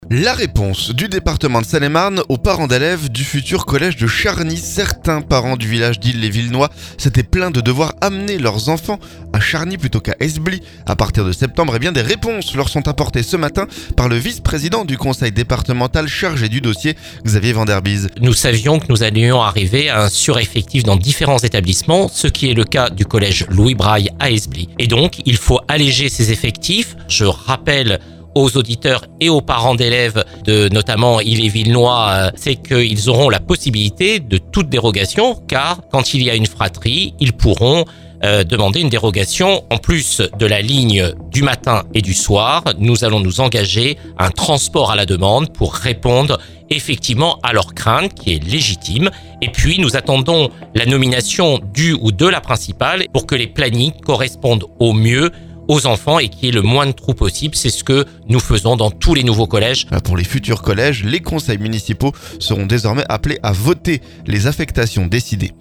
Eh bien des réponses leur sont apportés ce vendredi par le vice-président du Conseil départemental chargé du dossier, Xavier Vanderbise.